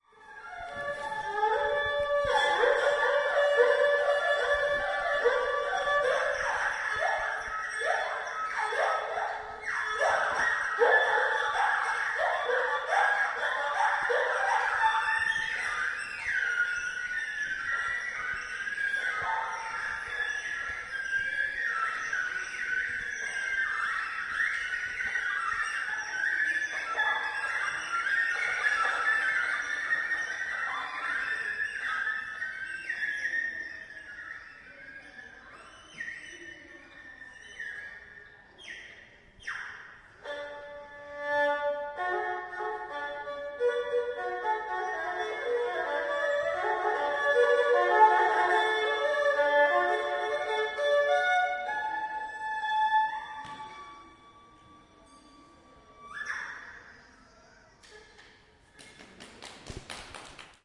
二胡划痕" gliss+screetch
描述：在二胡上刮出声音，二胡是一种两根弦的中国小提琴。
标签： 二胡 噪声 松香 划痕 尖叫
声道立体声